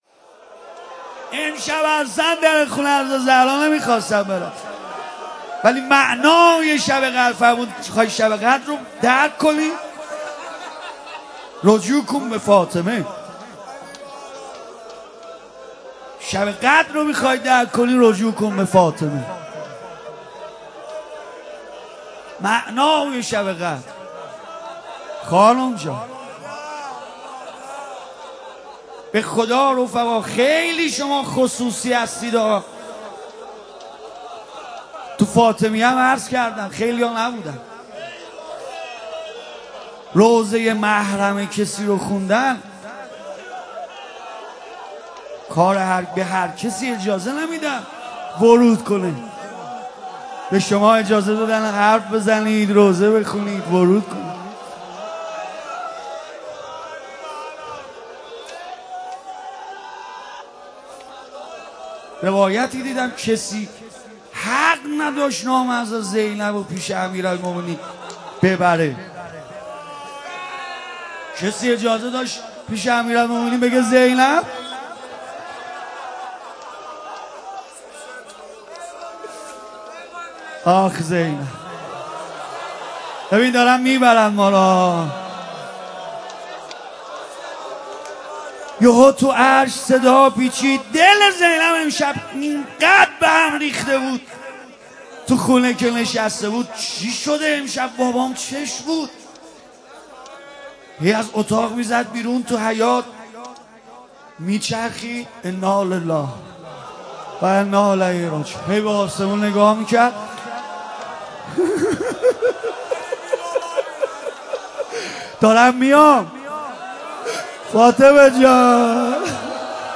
روضه و توسل جانسوز ویژه شهادت امیرالمؤمنین(ع)و شب نوزدهم
مداح